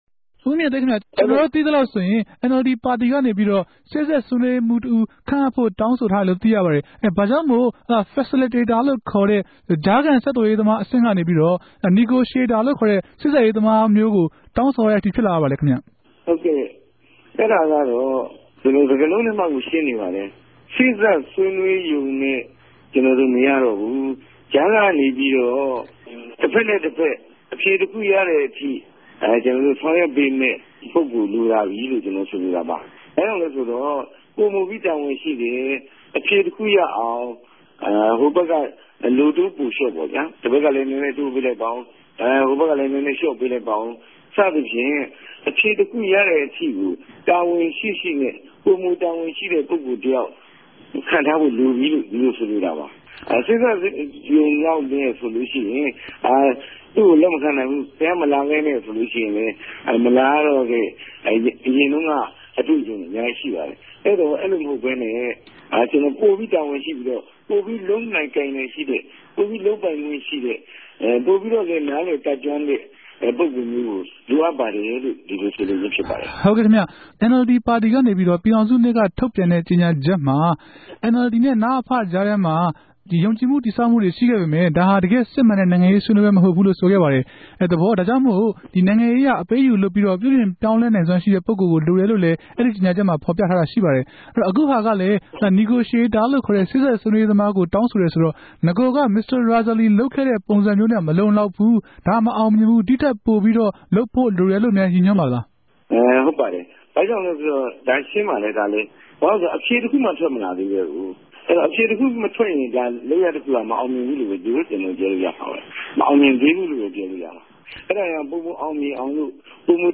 တယ်လီဖုန်းလိုင်း မကောင်းဘဲ အသံမုကည်လင်တဲ့အတြက်